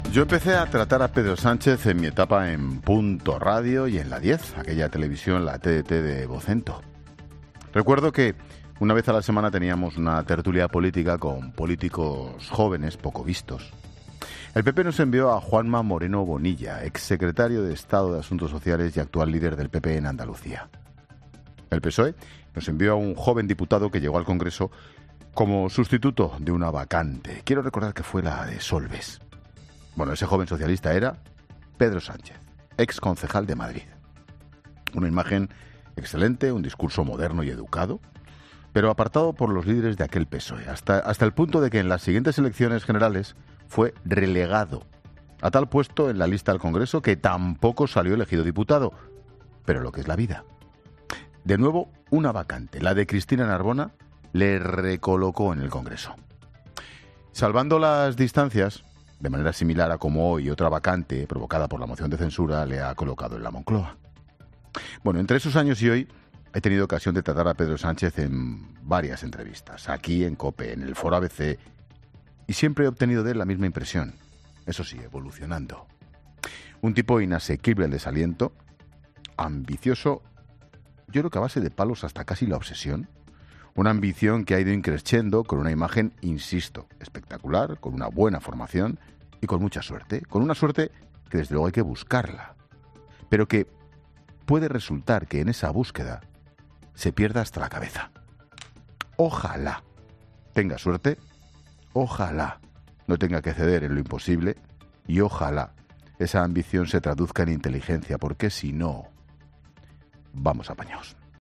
Monólogo de Expósito
Comentario de Ángel Expósito sobre la llegada de Sánchez a la Moncloa.